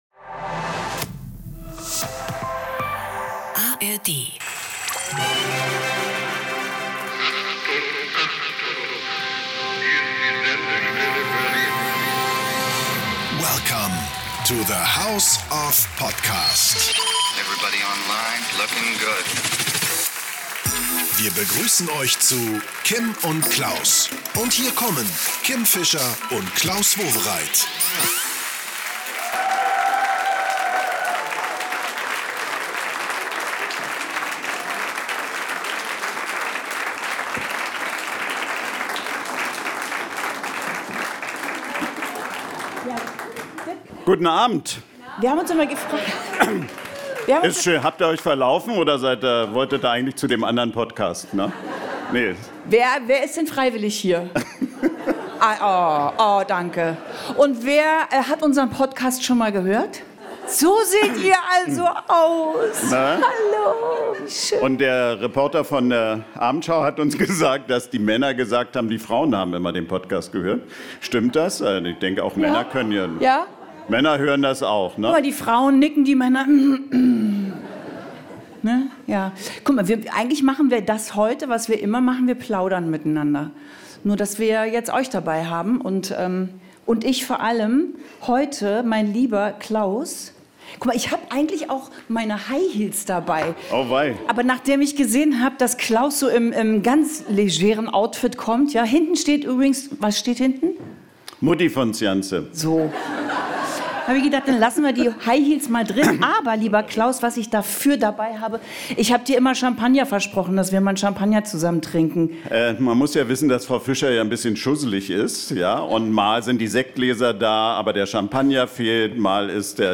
Die Show in der Dachlounge Studio 14 war schnell ausverkauft, aber hier kommen die Highlights zum nachhören.
Und klar, der Promianruf darf nicht fehlen: Schauspiel-Stars Anna Loos und Jan Josef Liefers sind in der Leitung. Die sind grade am Kofferpacken, und haben mehr Klamotten im Koffer als am Körper.